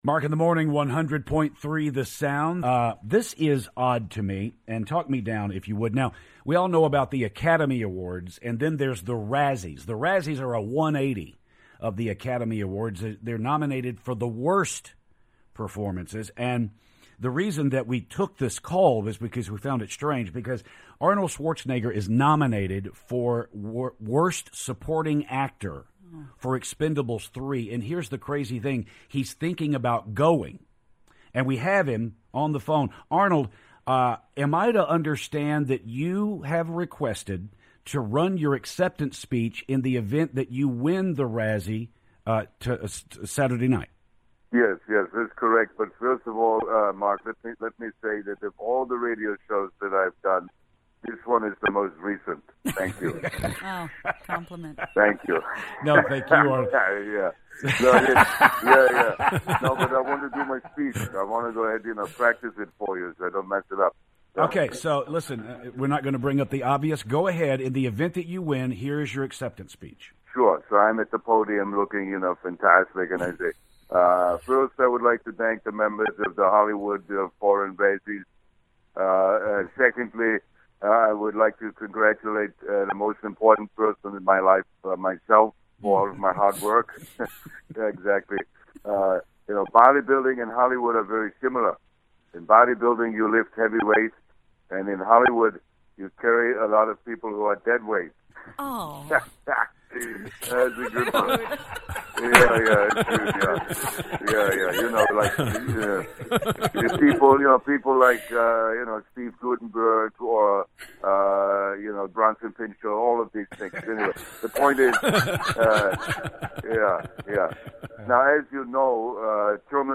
Arnold Schwarzenegger Calls The Show